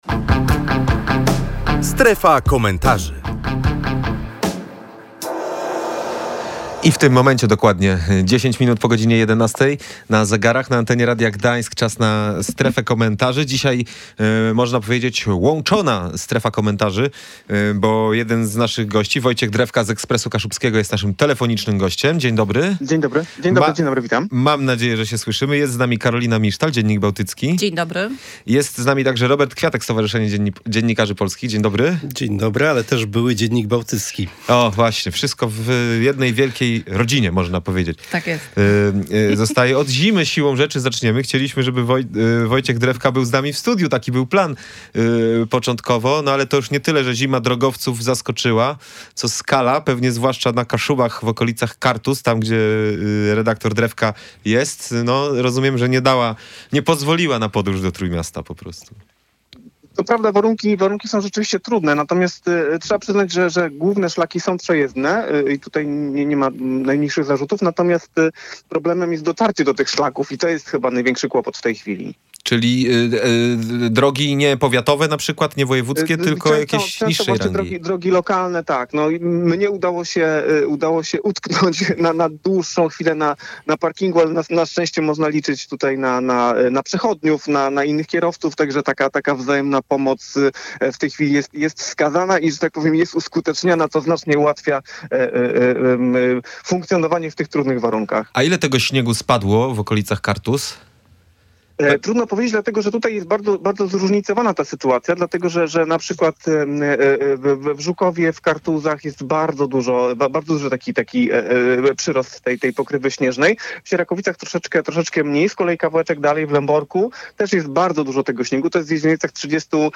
Na ten temat dyskutowaliśmy w „Strefie Komentarzy”.